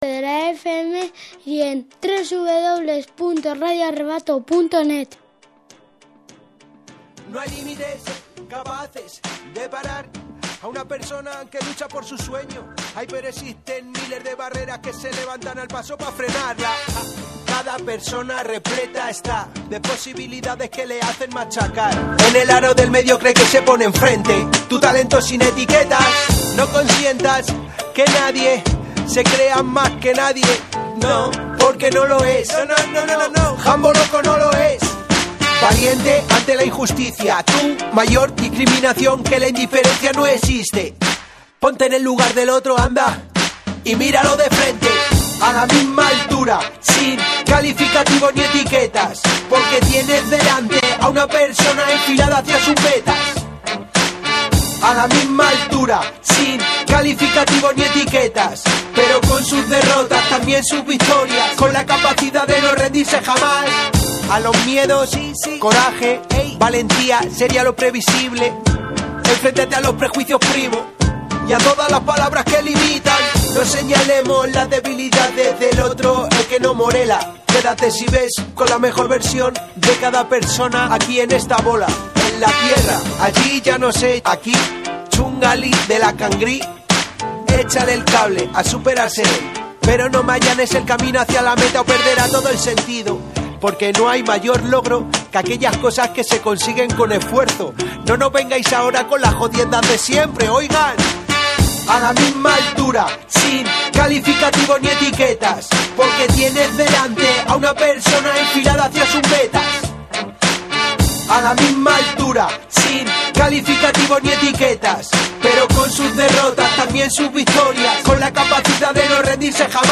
ENTREVISTA
Entrevista